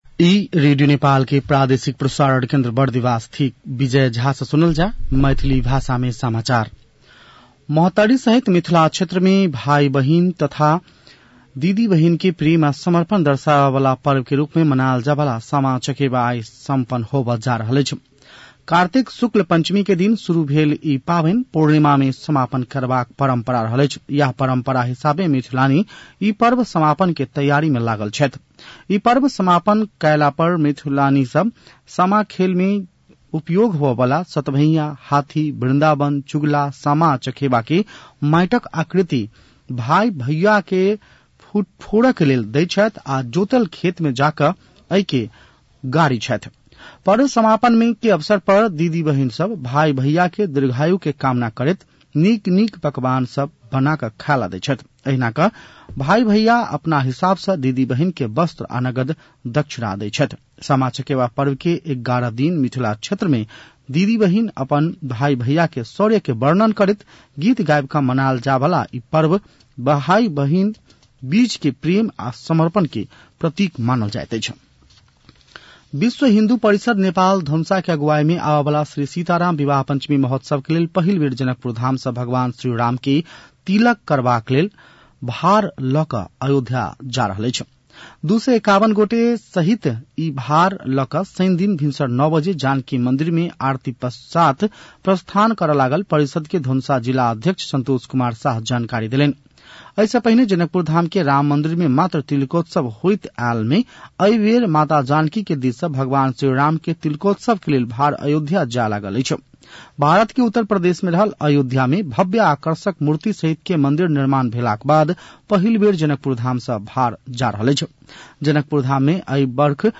मैथिली भाषामा समाचार : १ मंसिर , २०८१